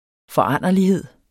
Udtale [ fʌˈɑnˀʌliˌheðˀ ]